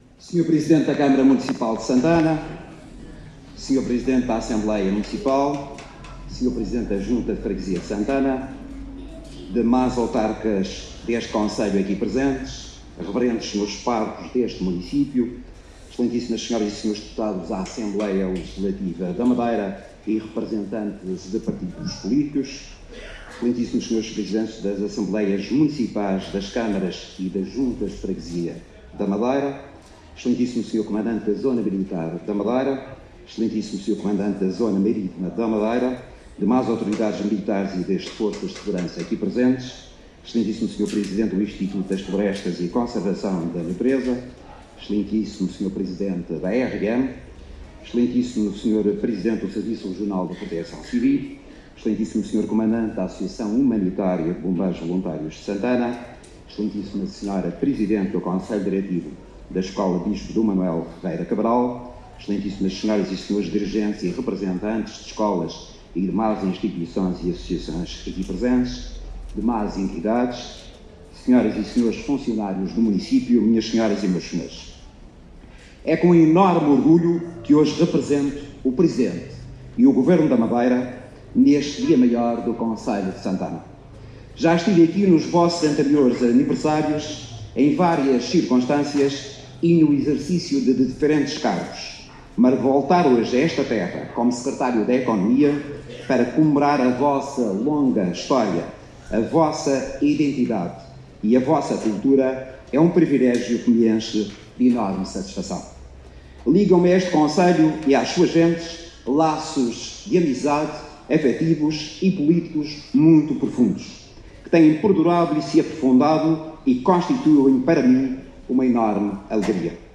Intervenção do Secretário Regional da Economia